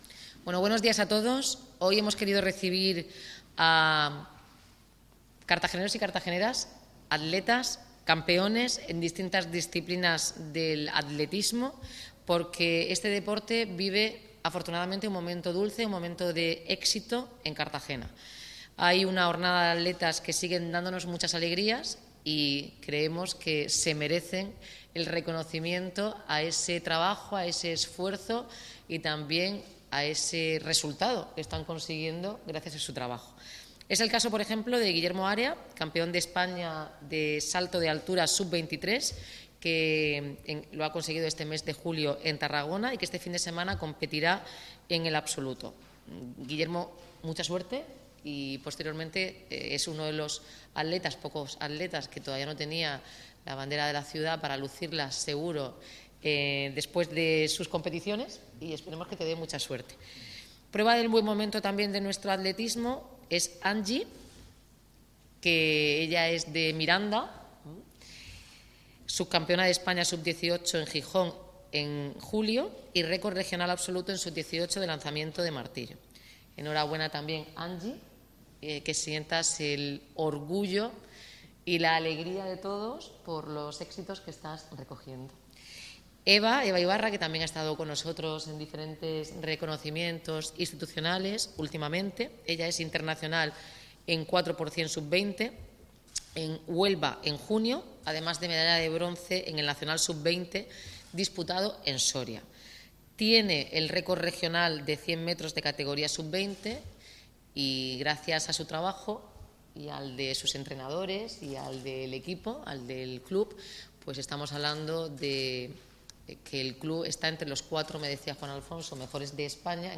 Enlace a Declaraciones Noelia Arroyo
Este miércoles, varios de estos deportistas han sido recibidos por la alcaldesa, Noelia Arroyo, y por el concejal de Deportes, José Martínez, en el Palacio Consistorial donde han podido hacer un balance de los logros conseguidos durante este año. Además, tras la recepción, ha anunciado el comienzo de las obras de la pista de atletismo municipal.